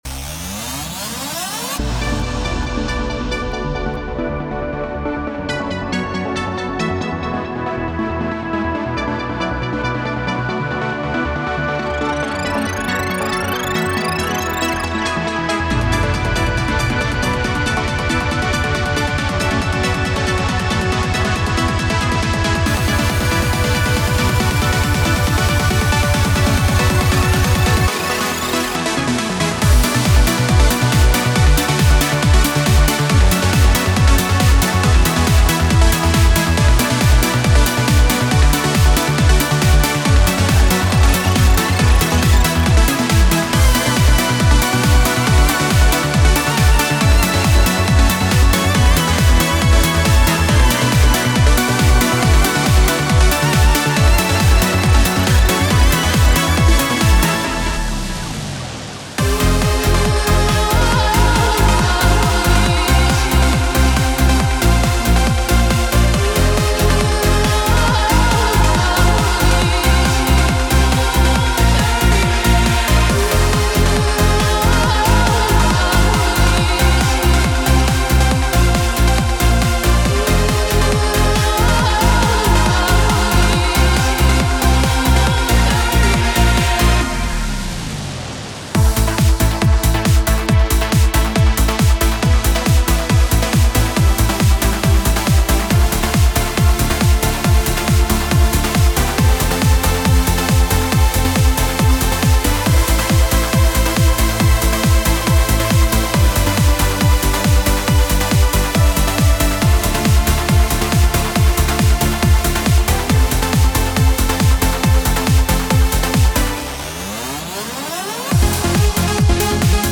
Eurodance Trance Uplifting Trance
Trance Era Vol.2 is the next soundset for Reveal Sound Spire with 111 Classic Trance Presets reproducing some of the best synth sounds from the Golden Age of Trance Music (1995-2005).